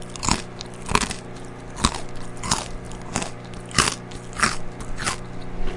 嘎吱嘎吱的咀嚼声
描述：咀嚼脆脆的ProVita饼干，嘴巴张开，闭合
Tag: 嘈杂 响亮 嘴巴 饼干 OWI 关闭 咀嚼 松脆 香酥 食品 紧缩 饮食 打开 咀嚼